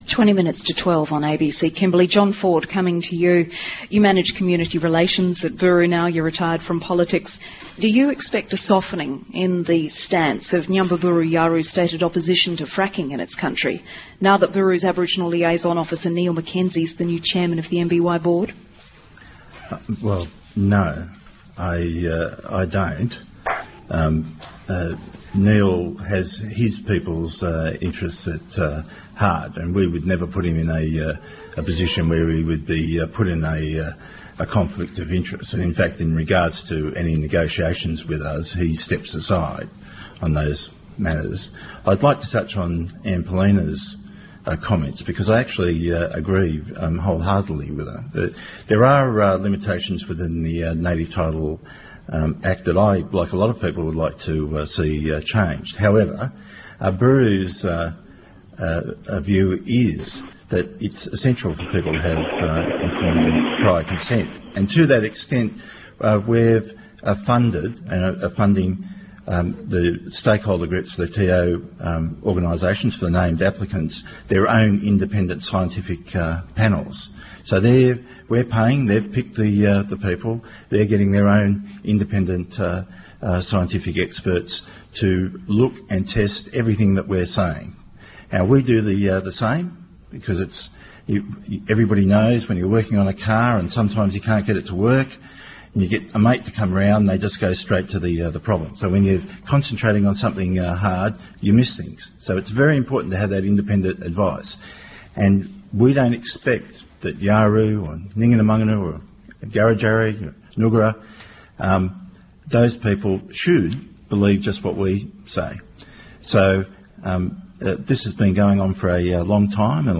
About 40 people gathered at the ABC studios in Broome to hear a discussion on the practice of hydraulic fracturing, with a focus on the Canning Basin.